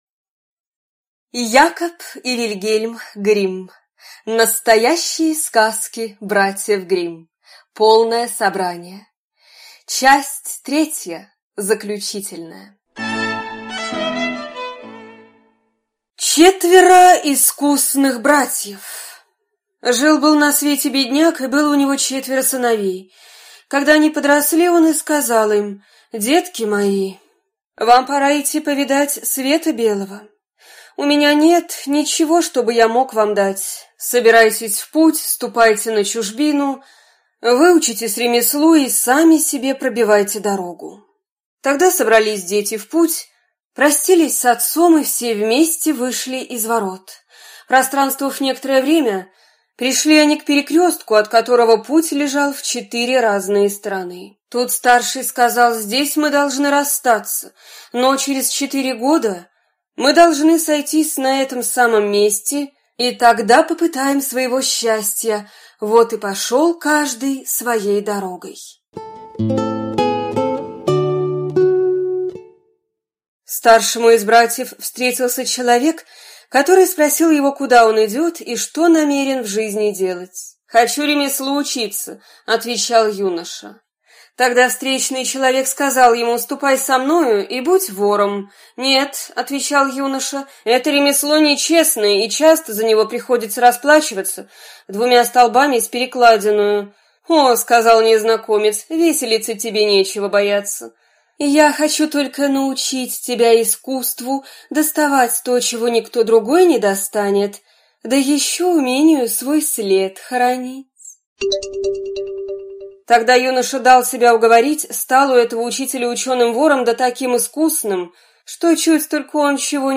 Аудиокнига Настоящие сказки братьев Гримм. Часть 3 | Библиотека аудиокниг